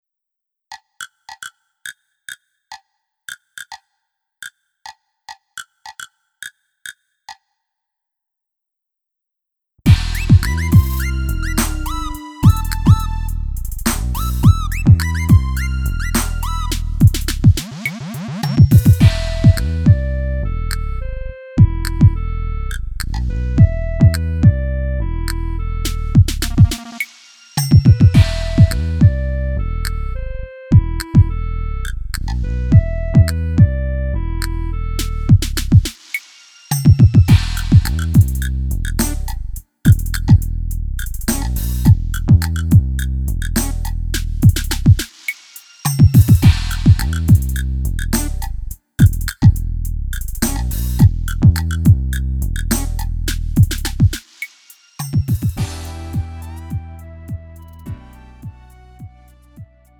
Lite MR은 저렴한 가격에 간단한 연습이나 취미용으로 활용할 수 있는 가벼운 반주입니다.
음정 원키
장르 가요